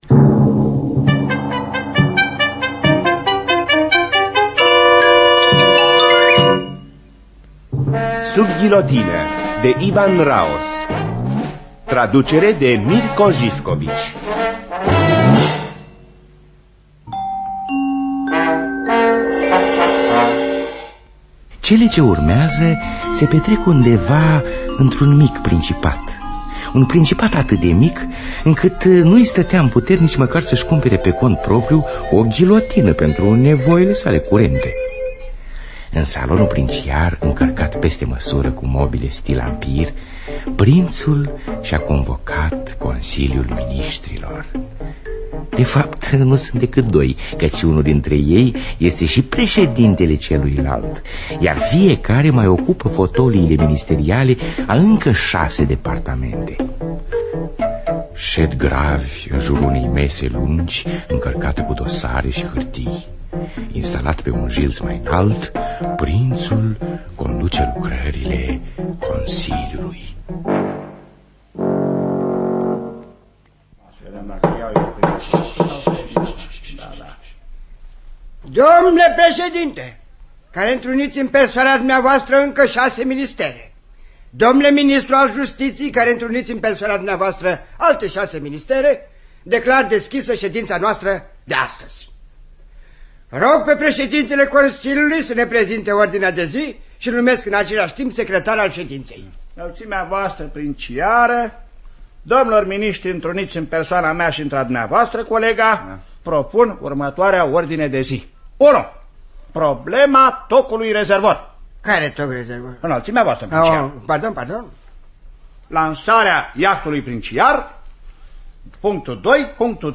Dramatizarea şi adaptarea radiofonică